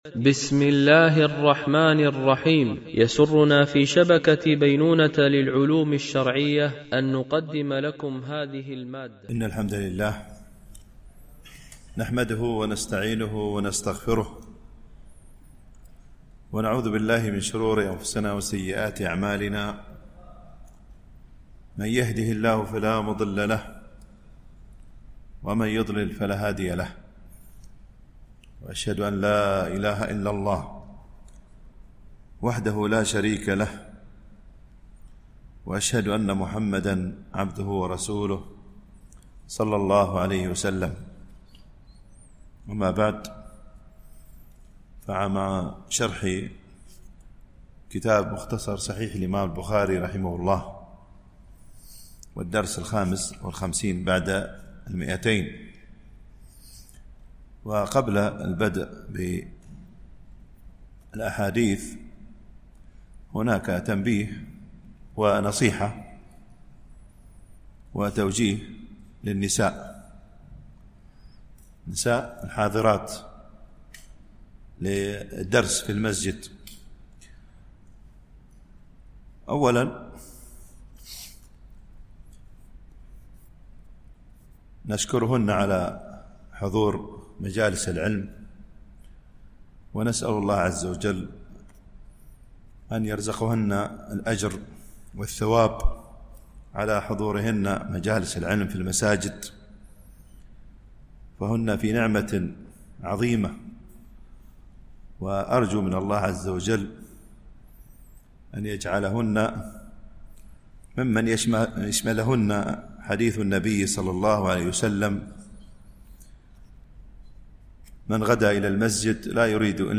- الجزء السابع - الحديث 1547 - 1551 ) الألبوم: شبكة بينونة للعلوم الشرعية التتبع: 255 المدة: 50:55 دقائق (23.32 م.بايت) التنسيق: MP3 Mono 44kHz 64Kbps (VBR)